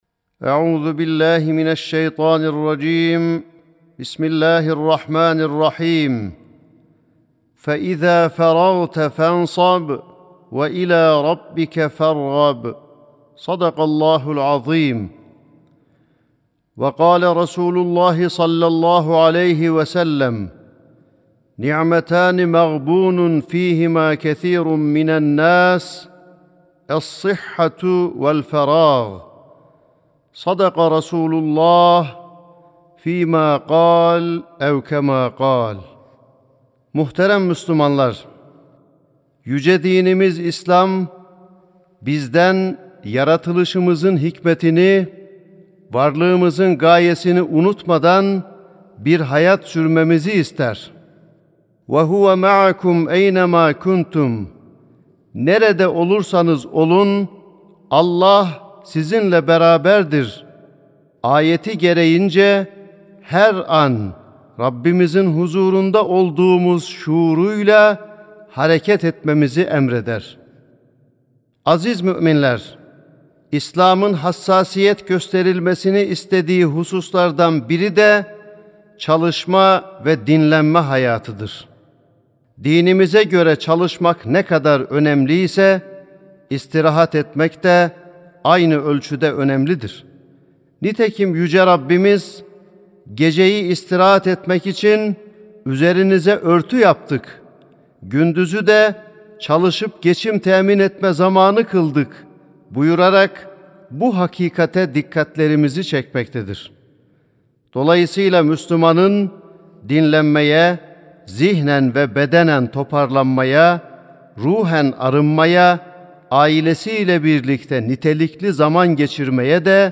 08.08.2025 Cuma Hutbesi: Sıla-i Rahimle Bereketlenen Tatil (Sesli Hutbe, Türkçe, İngilizce, Arapça, Almanca, Rusça, İspanyolca, İtalyanca, Fransızca)
Sesli Hutbe (Sıla-i Rahimle Bereketlenen Tatil).mp3